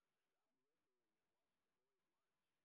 sp23_street_snr10.wav